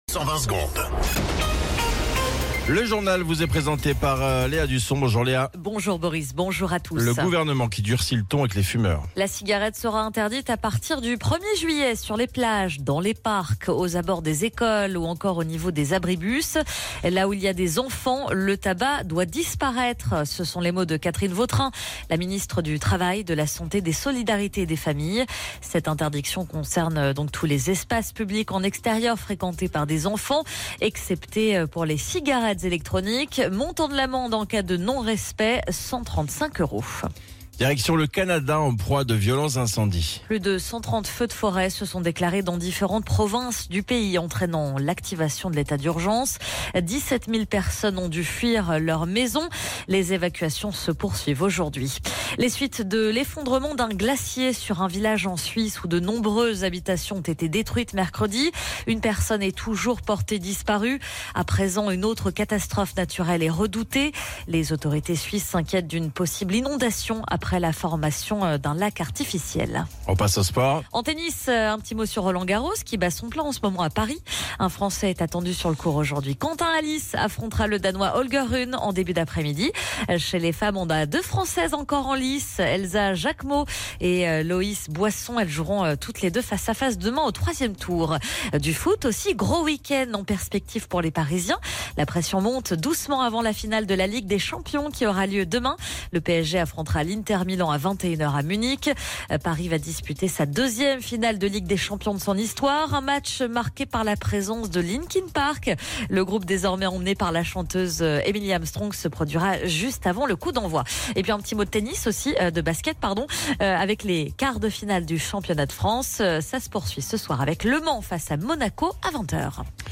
Flash Info National 30 Mai 2025 Du 30/05/2025 à 07h10 .